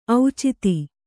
♪ auciti